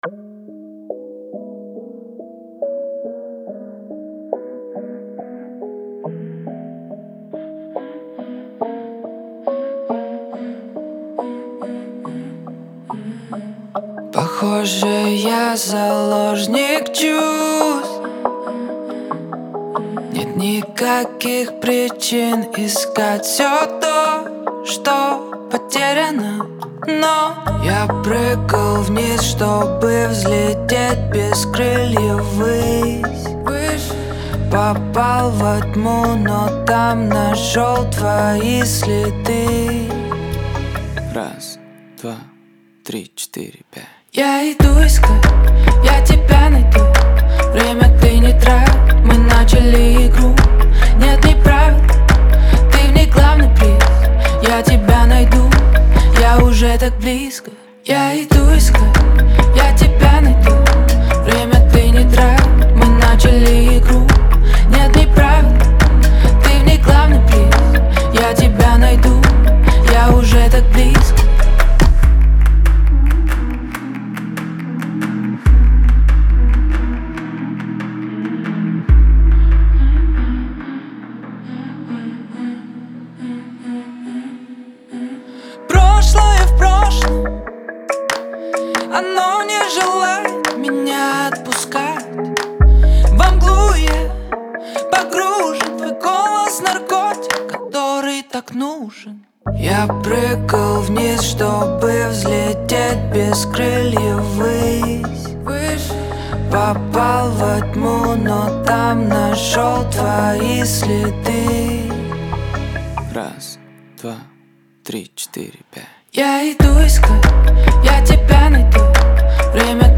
энергичная песня в жанре поп-рок